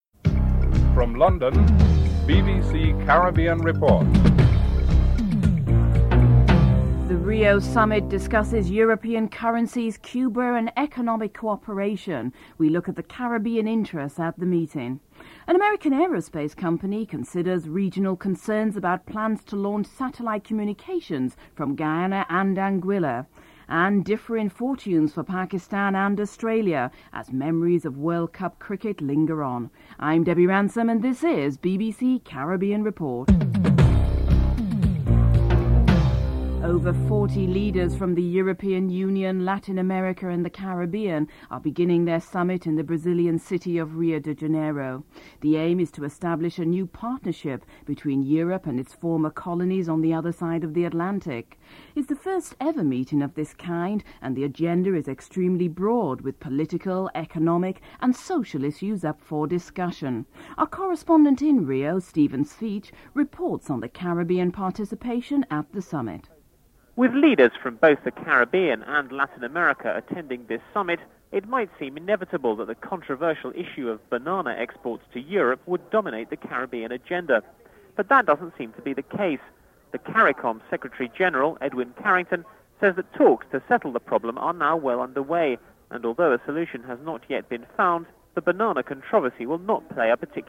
Headlines